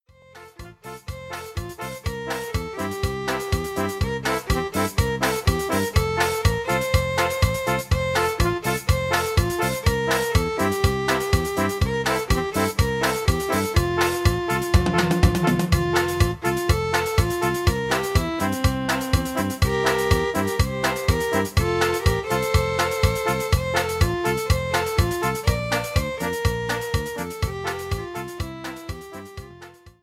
84-Duranguense-BZ.mp3